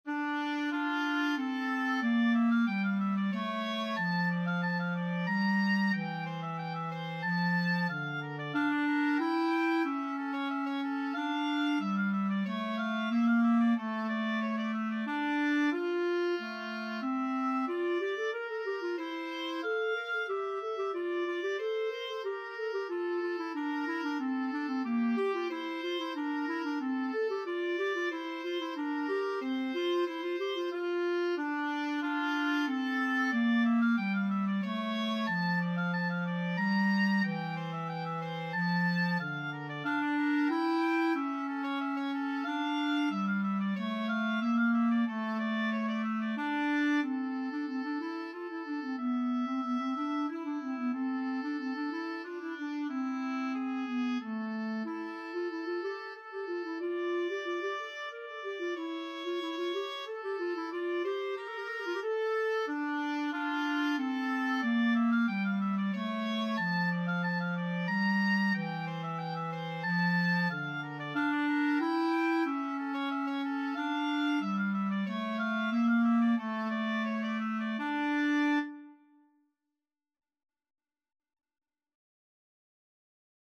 Free Sheet music for Clarinet-Oboe Duet
3/2 (View more 3/2 Music)
D minor (Sounding Pitch) E minor (Clarinet in Bb) (View more D minor Music for Clarinet-Oboe Duet )
Allegro Moderato = c. 92 (View more music marked Allegro)
Classical (View more Classical Clarinet-Oboe Duet Music)